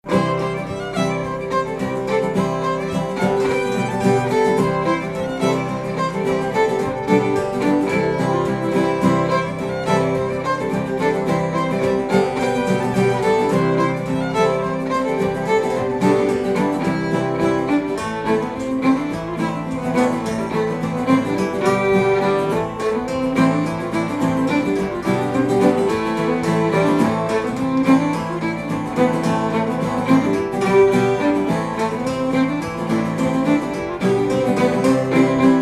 Original New England-style dance tunes
guitar, bouzouki
fiddle, viola
cittern, cross-tuned guitar